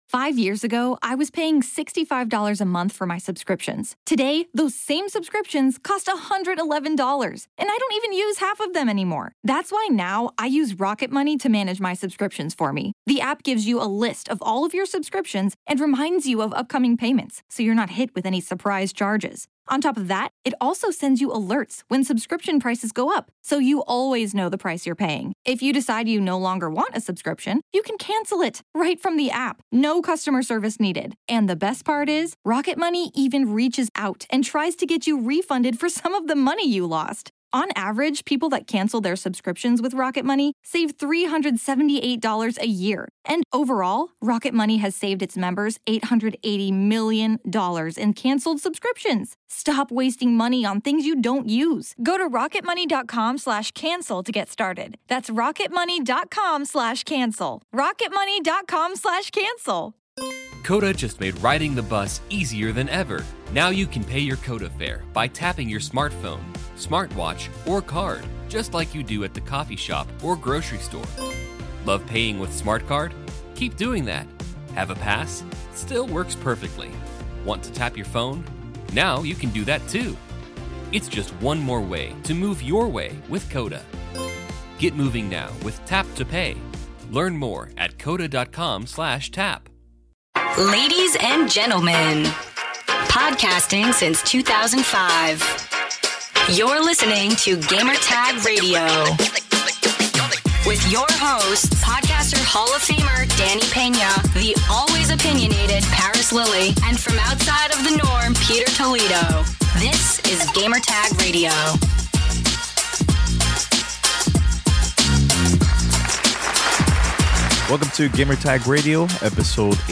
Gamertag Radio crew answer questions from the listeners about Half Life 3, favorite hobbies, Stadia and other topics.